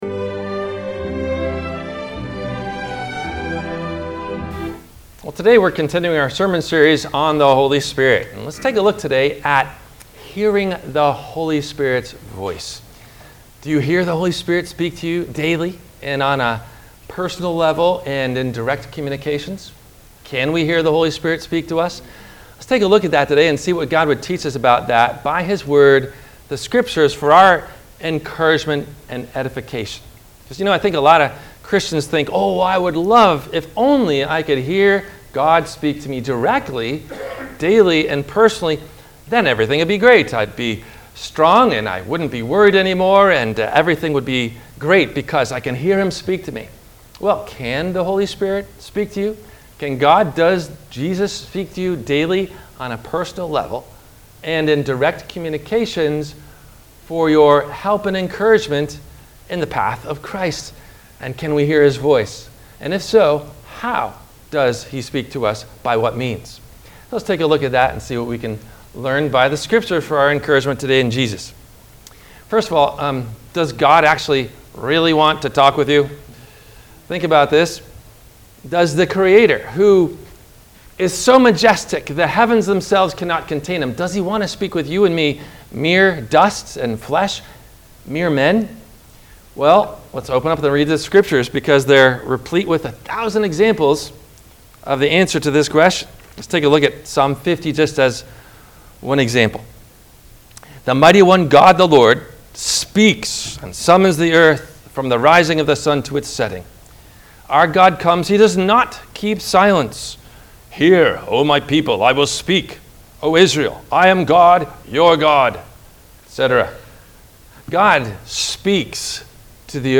No questions asked before the Sermon message.
WMIE Radio – Christ Lutheran Church, Cape Canaveral on Mondays from 12:30 – 1:00